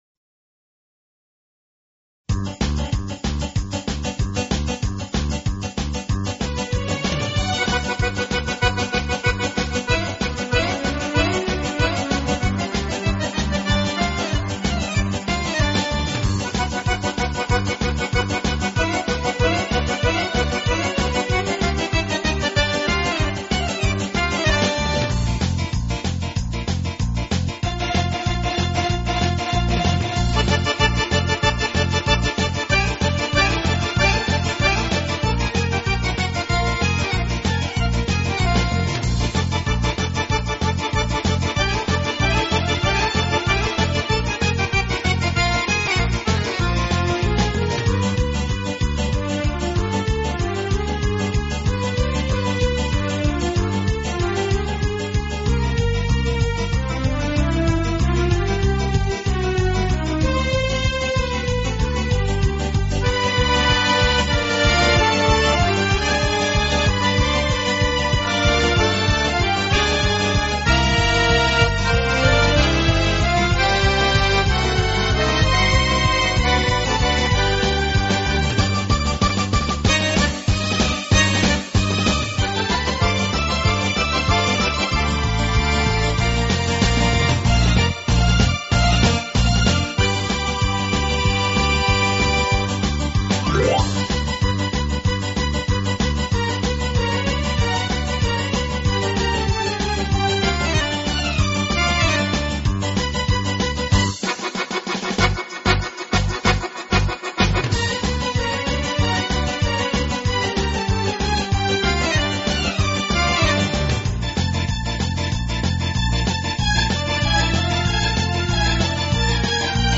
Acoustic（原音）是指原声乐器弹出的自然琴声（原音），制作录音绝对不含味精，乐
本套CD音乐之音源采用当今世界DVD音源制作最高标准：96Khz/24Bit取样录制，
极高的清晰度，准确的结像力，极宽的动态范围，更逼真的现场效果，更细腻纯